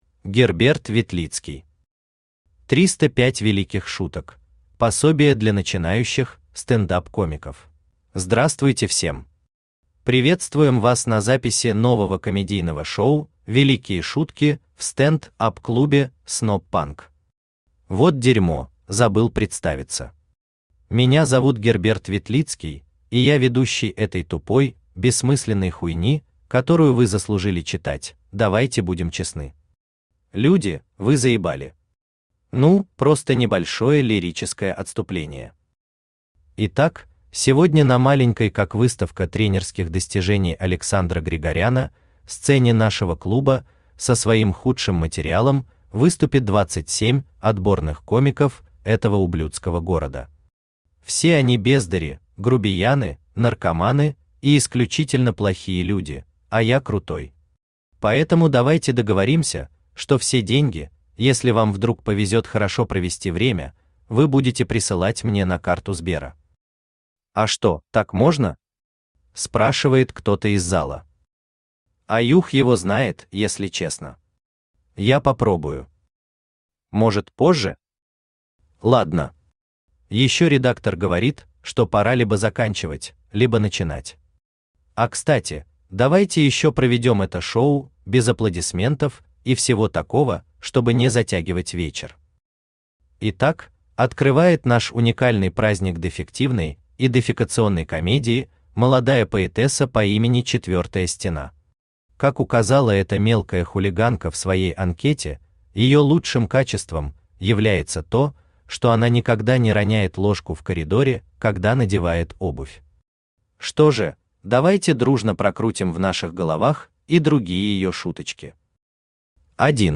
Аудиокнига 305 великих шуток: Пособие для начинающих стендап-комиков | Библиотека аудиокниг
Aудиокнига 305 великих шуток: Пособие для начинающих стендап-комиков Автор Герберт Ветлицкий Читает аудиокнигу Авточтец ЛитРес.